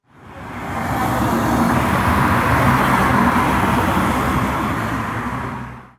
Busy City Street
Urban street ambience with passing traffic, pedestrian chatter, and distant horns
busy-city-street.mp3